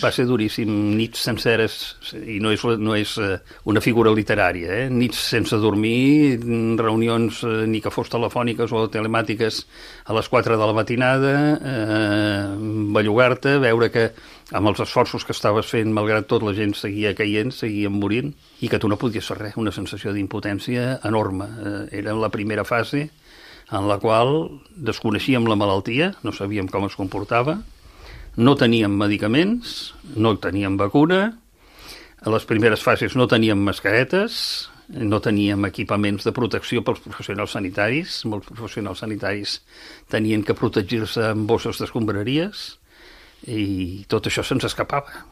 L’HORA DE LA GENT GRAN ha entrevistat el calellenc Joan Guix, qui va ser secretari de Salut Pública de la Generalitat de Catalunya durant la primera onada de la pandèmia de la Covid, que ha recordat com un moment d’extrema duresa en què l’equip polític i tècnic que estava al capdavant de la gestió de la crisi al país va sentir molta impotència.